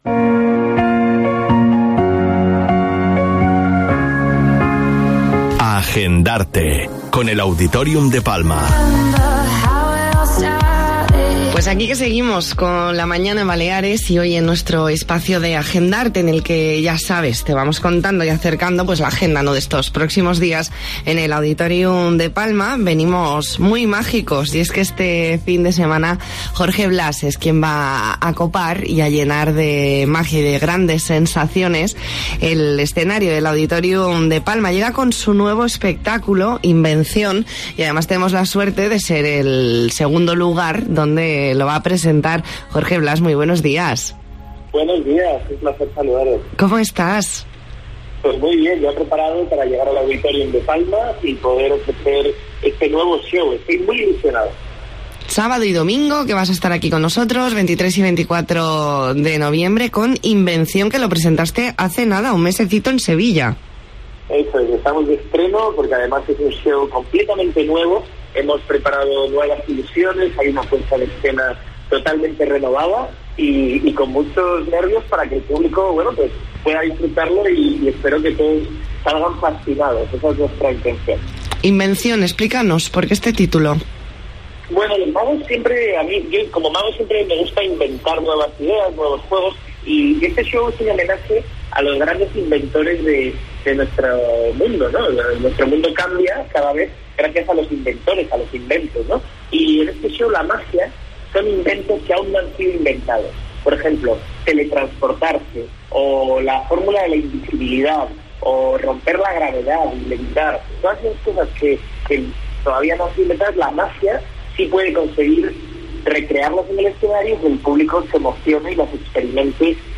Hablamos con el ilusionista Jorge Blass sobre su espectáculo “Invención” de este fin de semana en el Auditorium de Palma. Entrevista en 'La Mañana en COPE Más Mallorca', jueves 21 de noviembre de 2019.